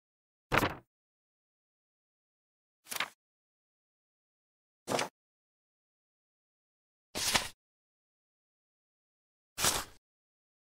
Paper Sound Effects (HD)
High quality paper sound effects for you to use in any project.
paper-sound-effects.mp3